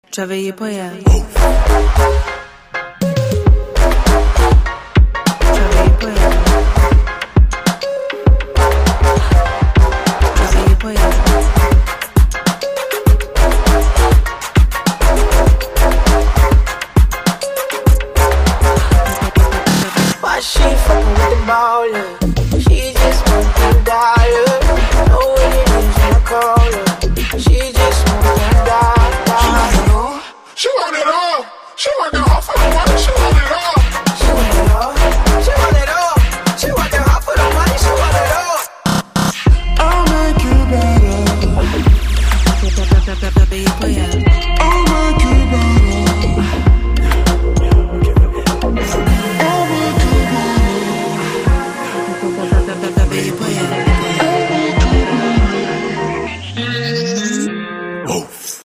• Качество: 128, Stereo
dance
Electronic
electro house